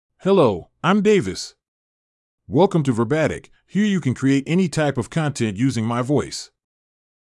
MaleEnglish (United States)
DavisMale English AI voice
Davis is a male AI voice for English (United States).
Voice sample
Davis delivers clear pronunciation with authentic United States English intonation, making your content sound professionally produced.